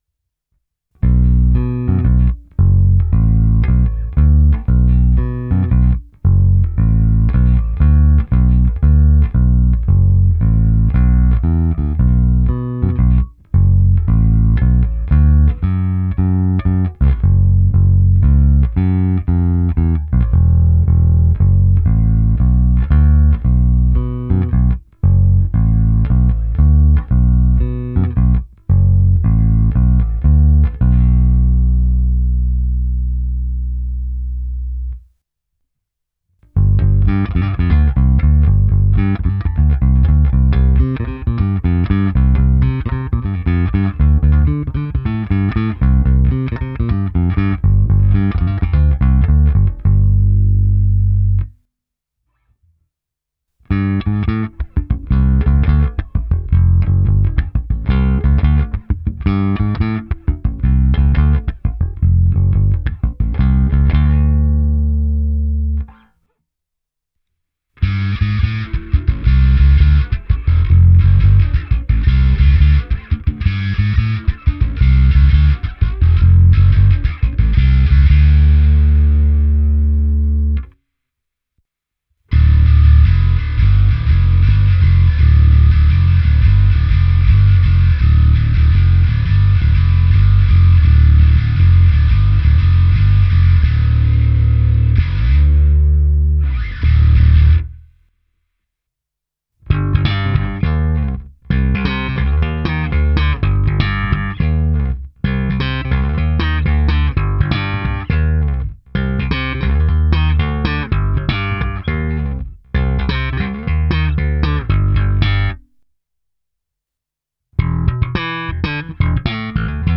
Ukázka přes Darkglass Harmonic Booster, kompresor TC Electronic SpectraComp a Darkglass Microtubes X Ultra se zapnutou simulací aparátu, v ukázce je použito i zkreslení a slap. Druhá ukázka zkreslení je hraná trsátkem na struně E podladěné do D.